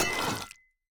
sounds / item / axe / wax_off3.ogg
wax_off3.ogg